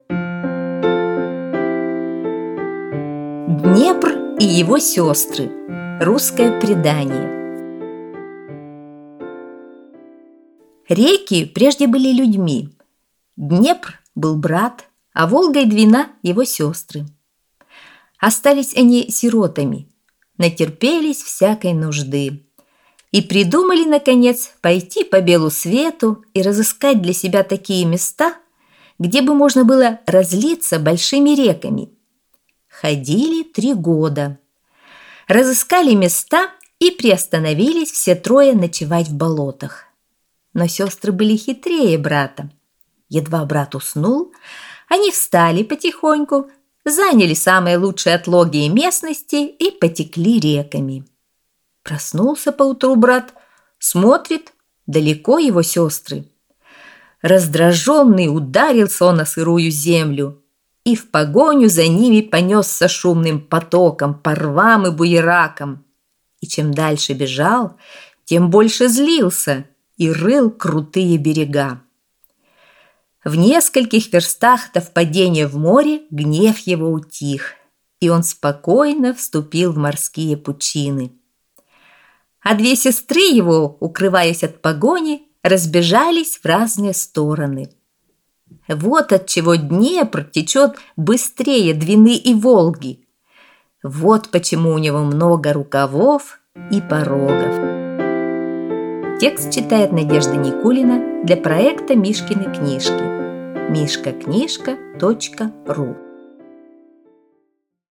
Аудиосказка «Днепр и его сёстры»